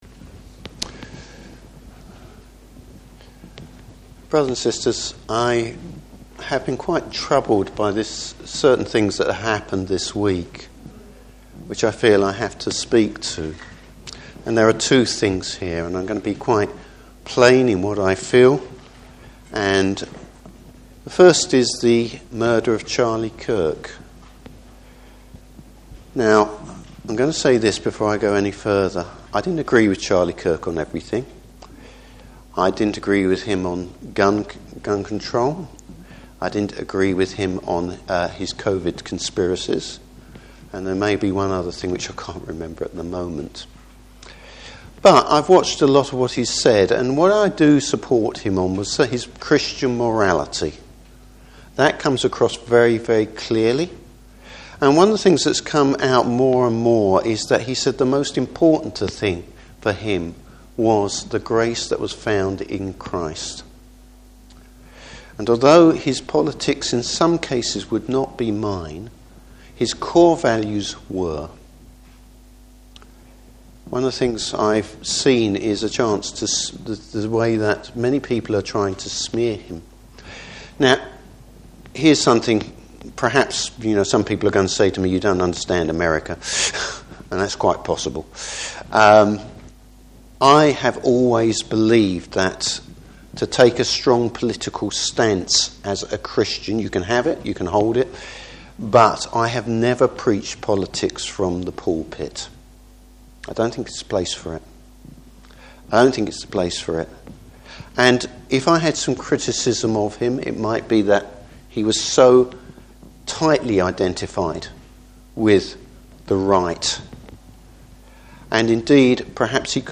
Service Type: Morning Service What’s the Christian’s priority?